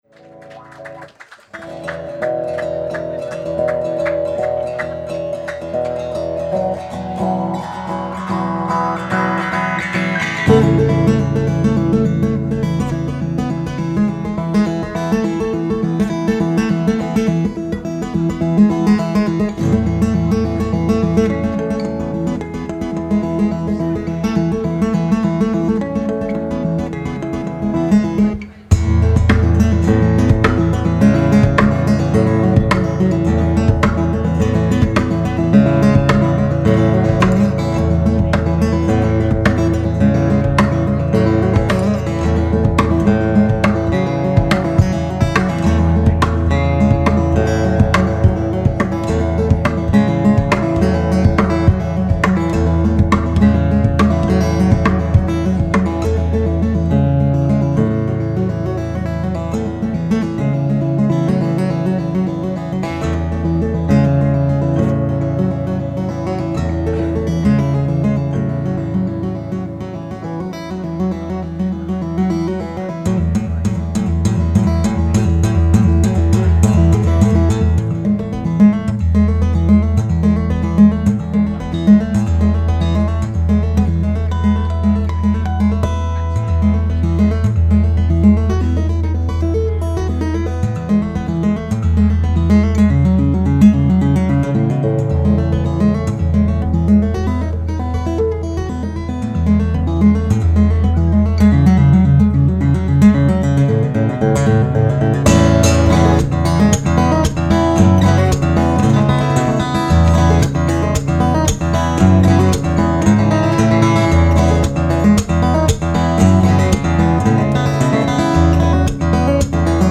Recorded Live at Tentertainment 2012